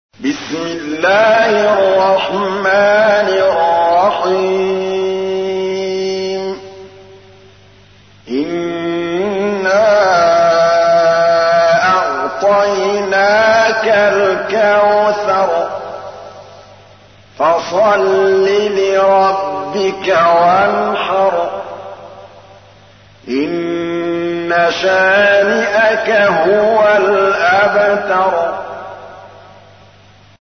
تحميل : 108. سورة الكوثر / القارئ محمود الطبلاوي / القرآن الكريم / موقع يا حسين